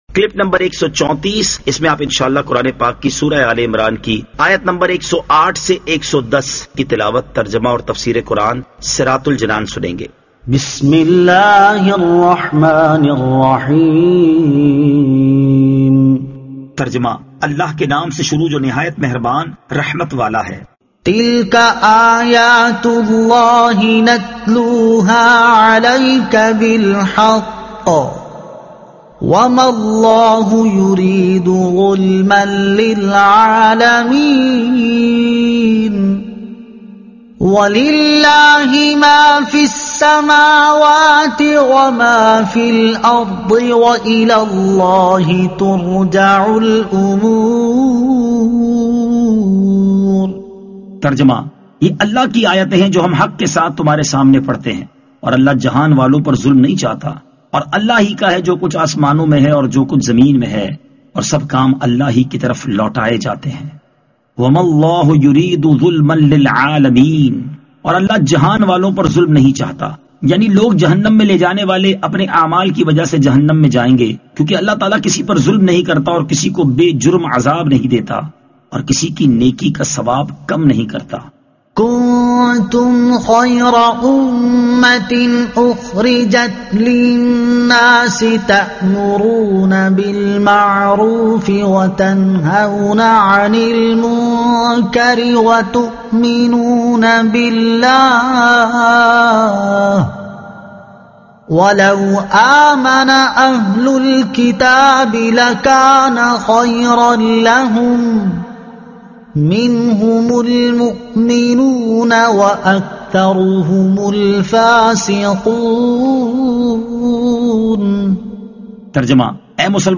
Surah Aal-e-Imran Ayat 108 To 110 Tilawat , Tarjuma , Tafseer